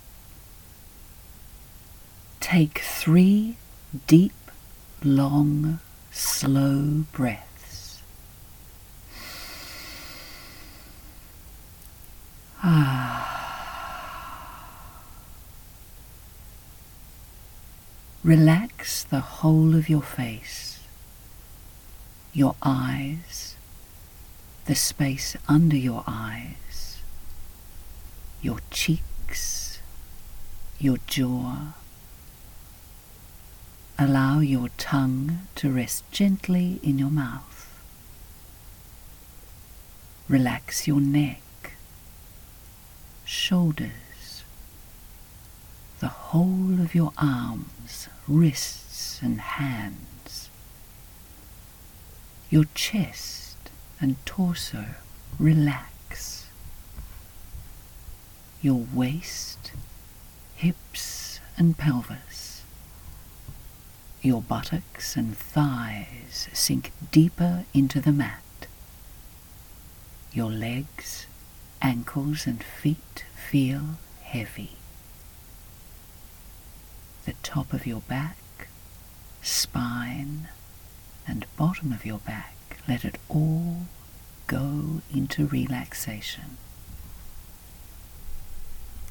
Female
English (Australian)
Narration
Meditation
Words that describe my voice are sensual, conversational, authoritative.
All our voice actors have professional broadcast quality recording studios.
02188._Meditative.mp3